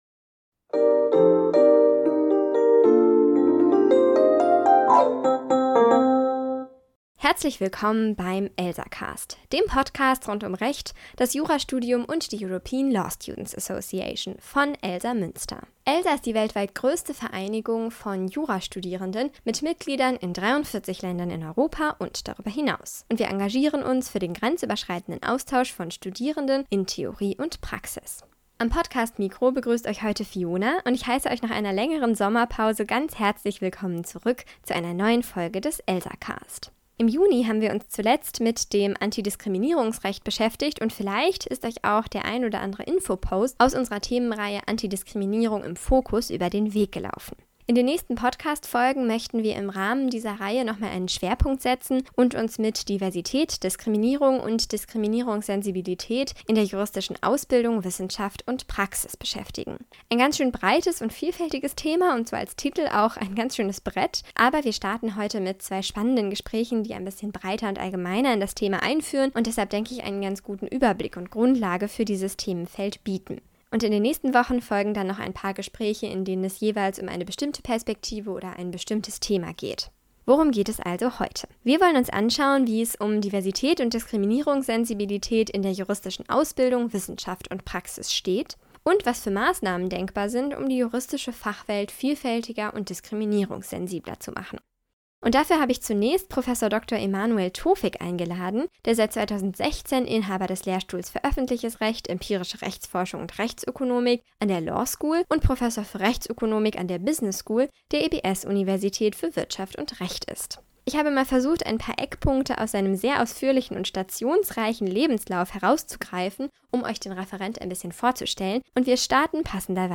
Among other things, the discussion focused on why women and people with a migration background receive lower marks in the oral state examinations than male candidates without a migration background and what role the composition of the examination board plays in this. The speakers also discussed how an inclusive teaching can be designed and why it is a central part of teaching to sensitize law students to discrimination and the reality of other people’s lives.